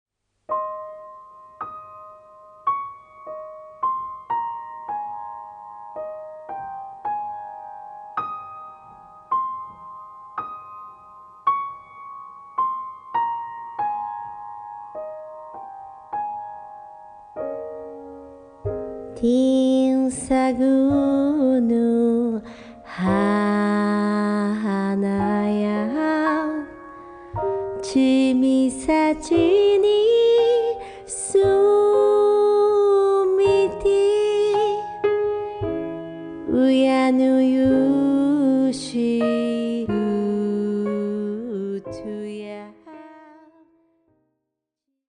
Traditional Okinawan music
Recorded on Dec. 22nd and 23rd, 2024 at Studio Dede, Tokyo